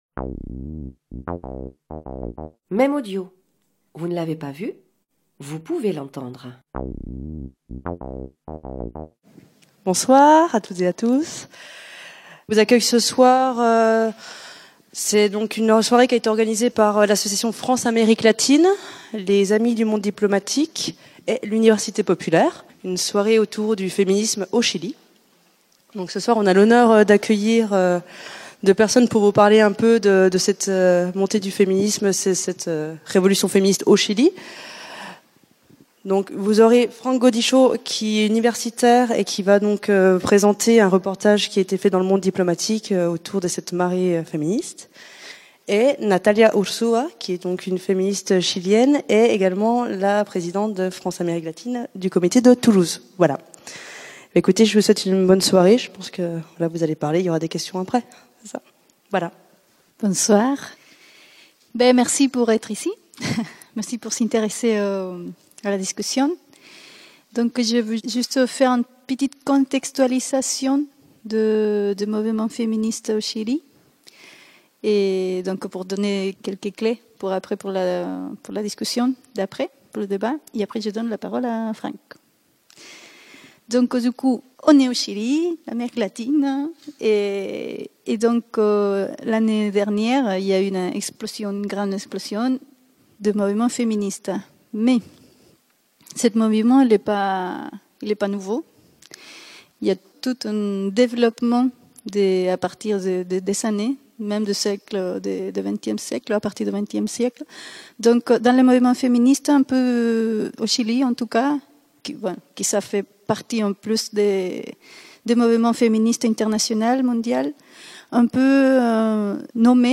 La conférence-débat (au format MP3)